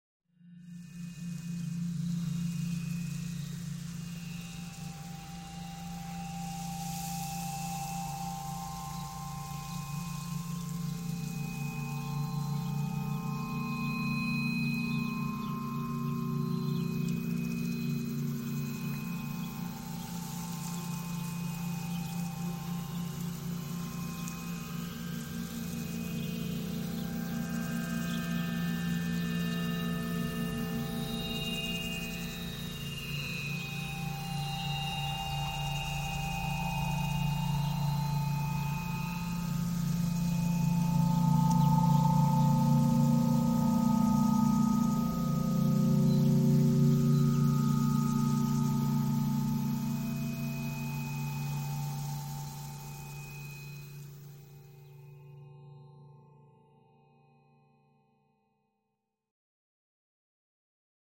Cette création sonore électroacoustique